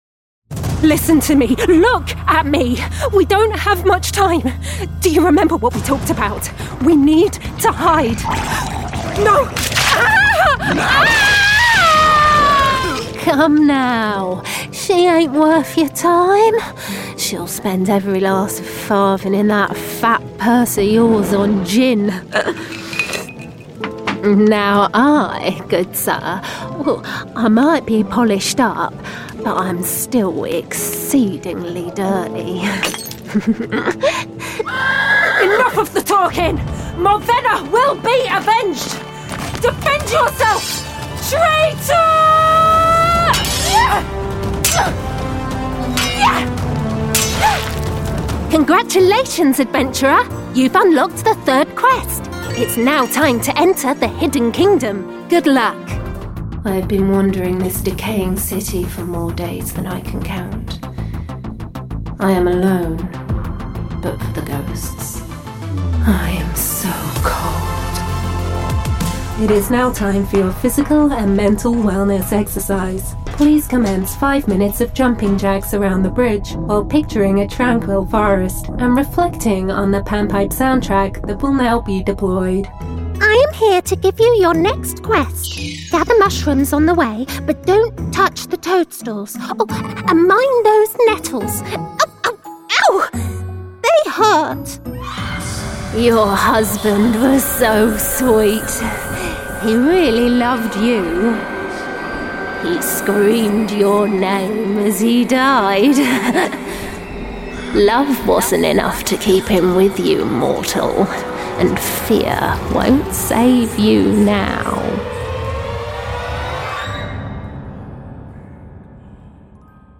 Videojuegos
Con un acento inglés neutro/RP, mi voz natural es brillante, atractiva y fresca. Se la ha descrito como limpia, segura y, lo más importante, ¡versátil!
Cabina de paredes sólidas hecha a medida con tratamiento acústico completo